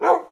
bark2.mp3